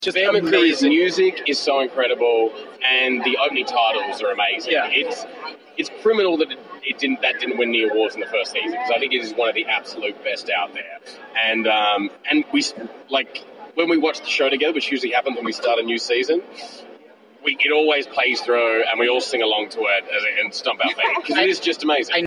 Hi-Def Ninja was able to sit down with the cast of BLACK SAILS at NYCC to talk to them about the show and its fourth and final season.